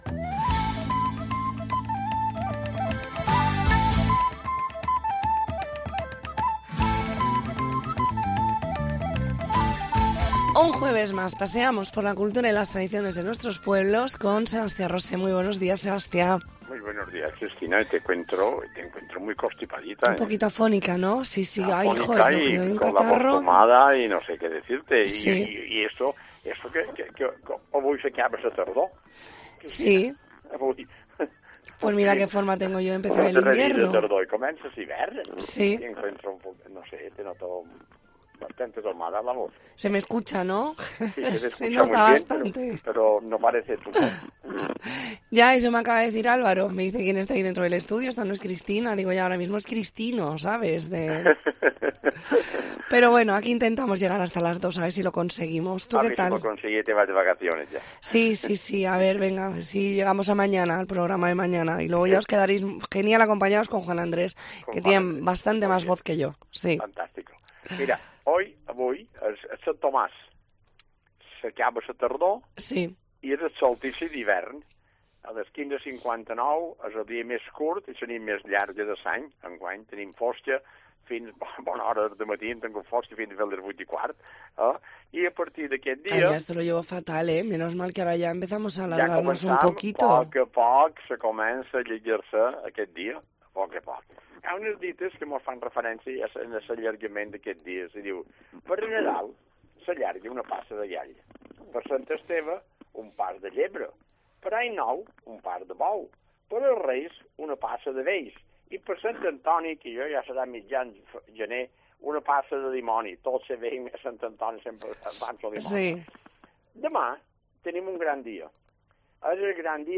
Redacción digital Madrid - Publicado el 21 dic 2023, 13:20 - Actualizado 21 dic 2023, 15:19 1 min lectura Descargar Facebook Twitter Whatsapp Telegram Enviar por email Copiar enlace Espacio semanal donde repasamos las costumbres mallorquinas y os contamos las ferias y fiestas de los próximos días. Entrevista en 'La Mañana en COPE Más Mallorca', jueves 21 de diciembre de 2023.